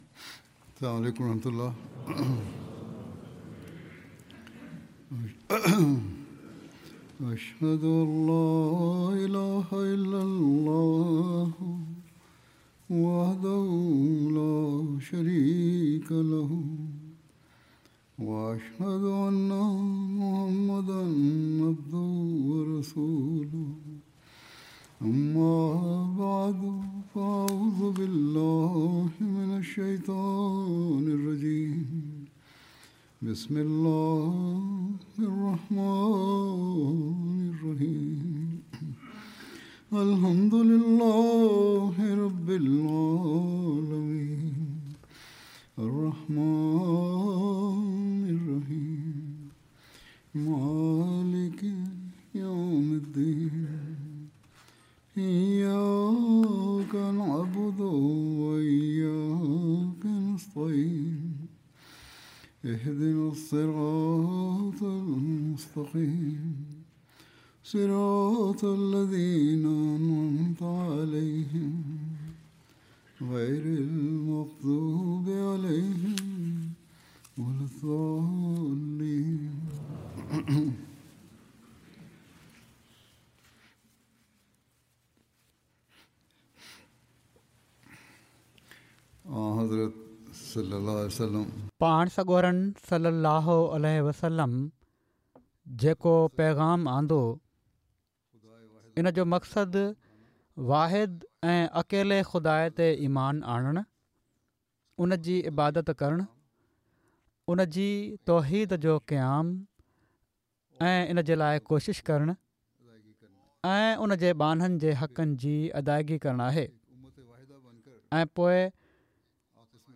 06 March 2026 Unity and Prayers: The Shield of The Ummah Sindhi Friday Sermon by Head of Ahmadiyya Muslim Community 1 h 0 min About Sindhi translation of Friday Sermon delivered by Khalifa-tul-Masih on March 6th, 2026 (audio)